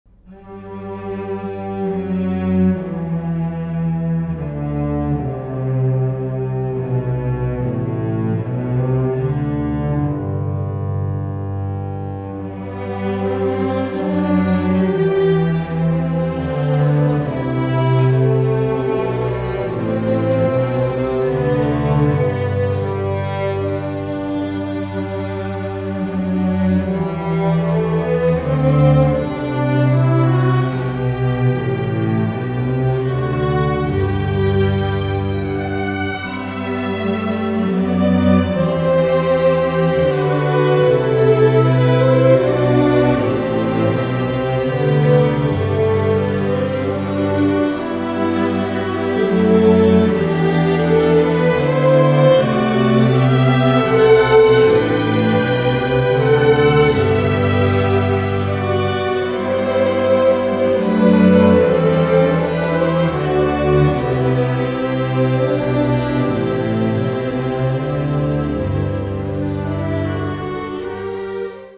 Chapel
violins:
violoncelli:
contrabass: